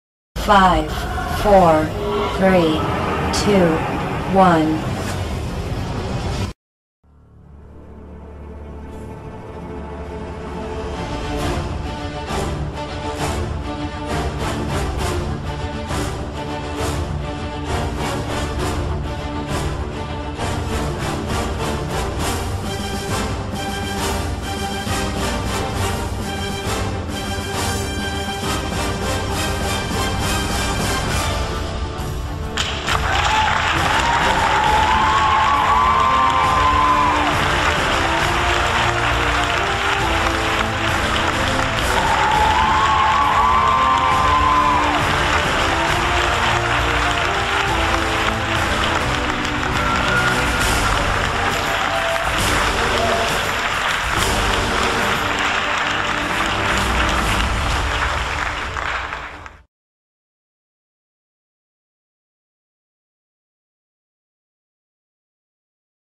หมวดหมู่: เพลงพื้นหลังวิดีโอ